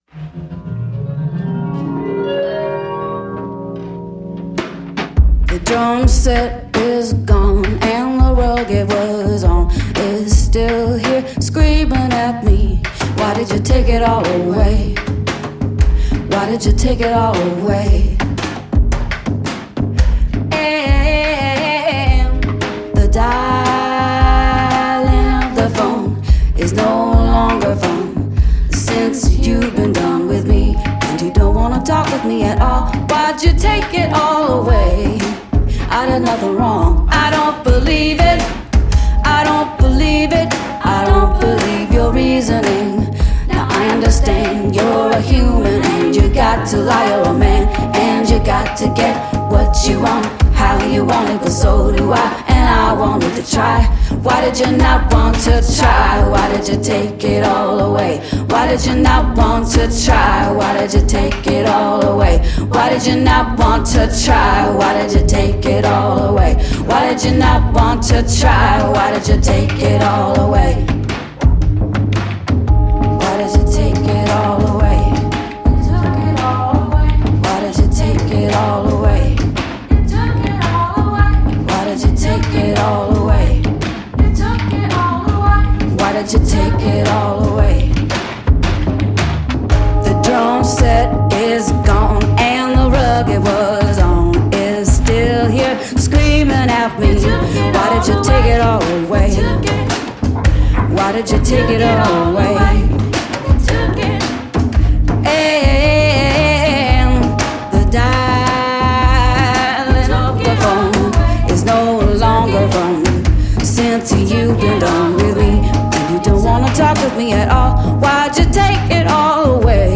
Album được thu âm từ năm 2015 đến năm 2020, phần lớn tại nhà